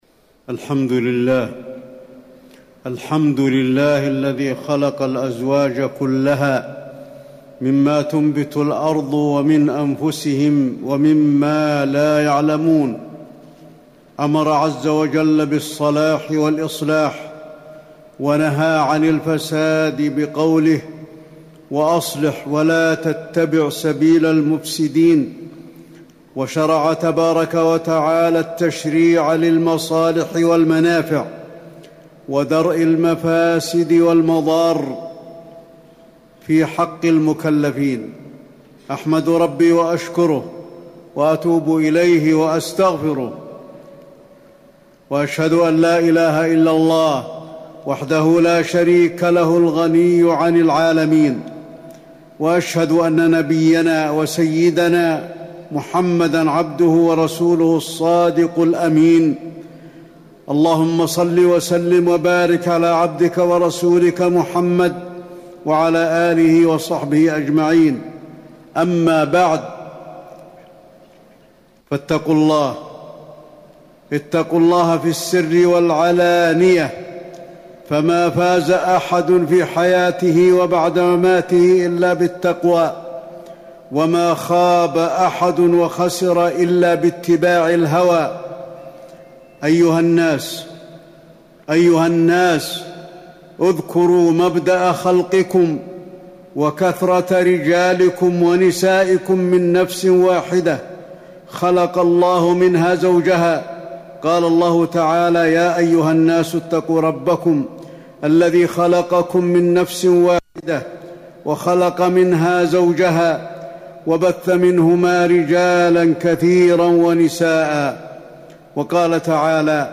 تاريخ النشر ٣ صفر ١٤٤٠ هـ المكان: المسجد النبوي الشيخ: فضيلة الشيخ د. علي بن عبدالرحمن الحذيفي فضيلة الشيخ د. علي بن عبدالرحمن الحذيفي الحقوق الزوجية وأسباب الطلاق